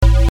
nt thiklead.wav